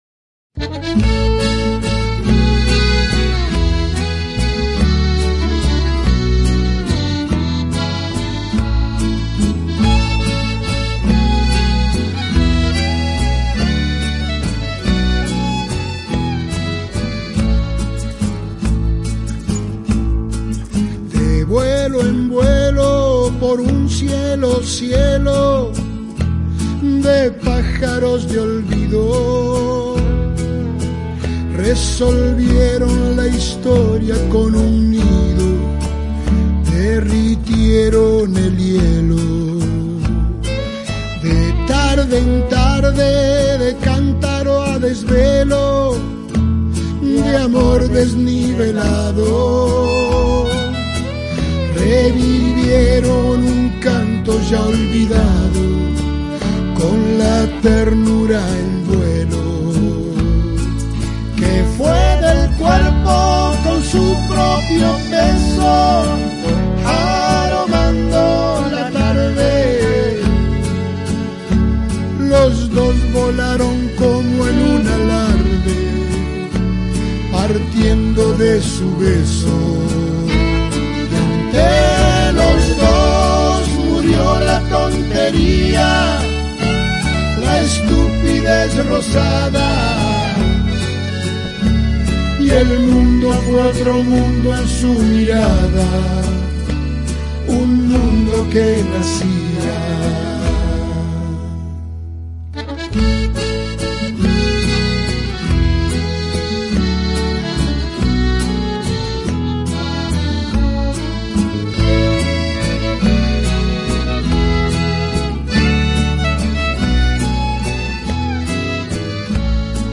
una ranchera
a ritmo de ranchera